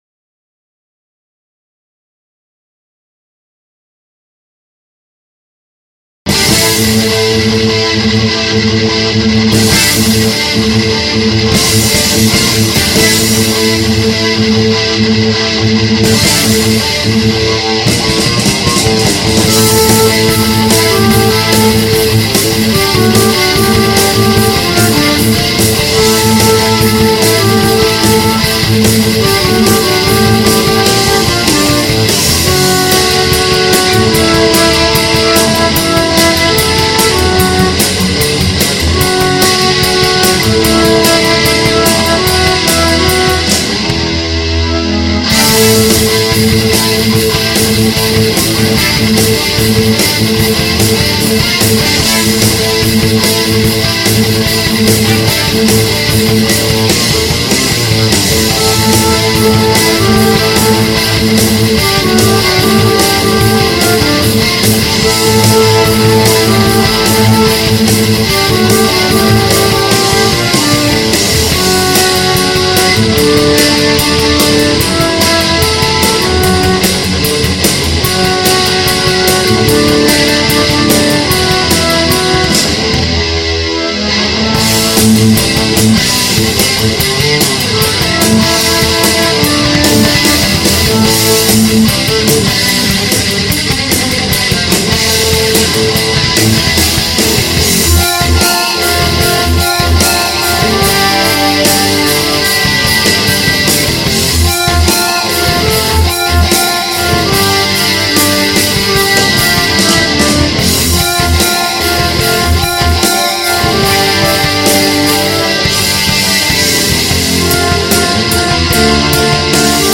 Pre Recorded Backing Track